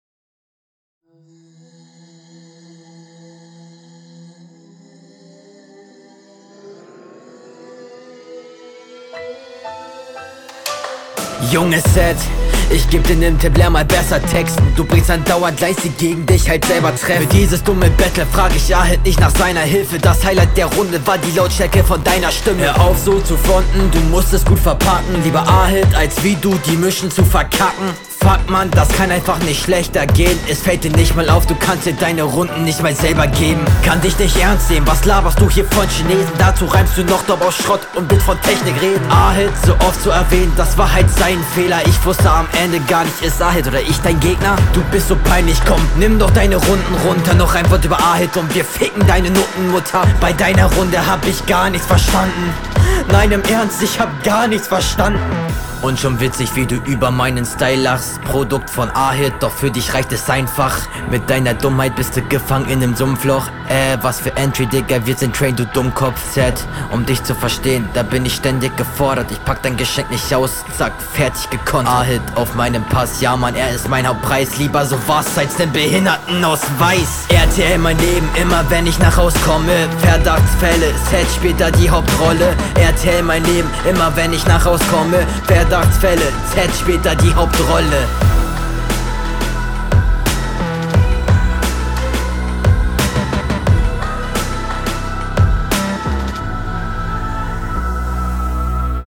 Flow:fand ich nicht schlecht, hattest auch gut variation drinne Text:gehst aufjeden gut auf deinen gegner …
Schöne Variationen die alle schon ganz solide klingen.